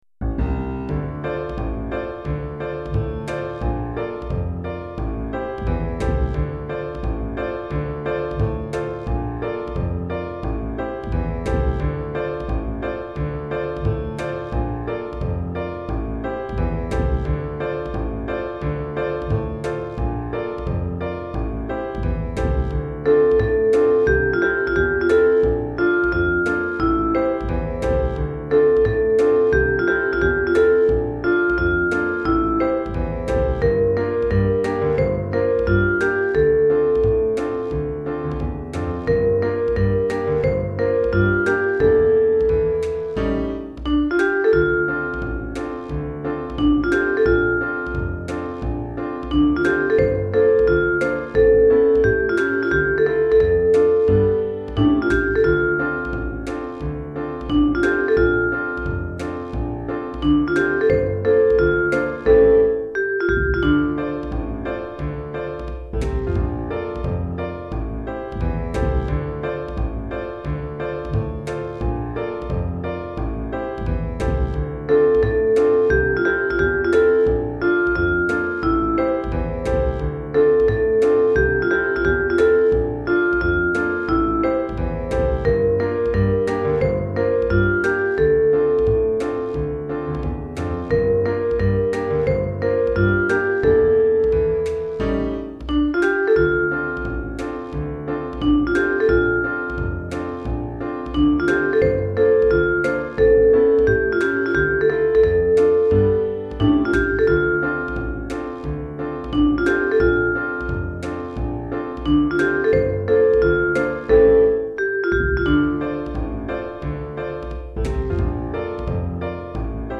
Chorale d'Enfants (10 à 12 ans) et Piano